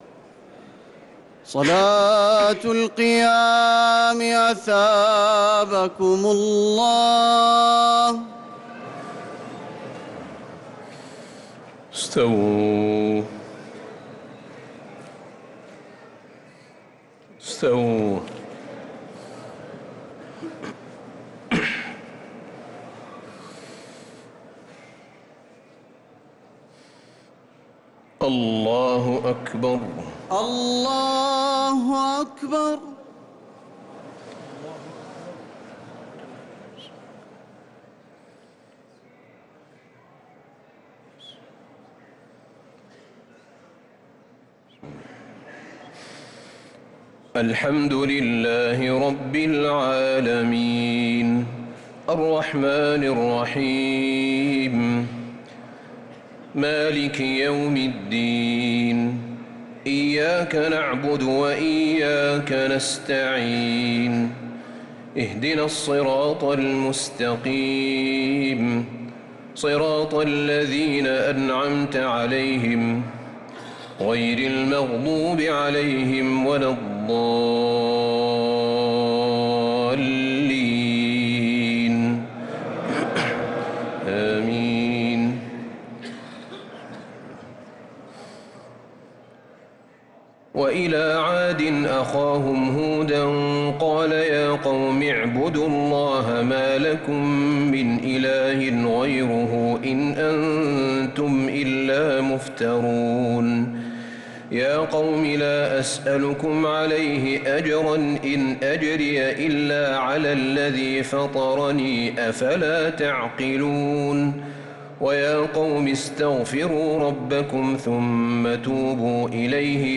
تراويح ليلة 16 رمضان 1446هـ من سورة هود (50-123) | Taraweeh 16th Ramadan 1446H Surat Hud > تراويح الحرم النبوي عام 1446 🕌 > التراويح - تلاوات الحرمين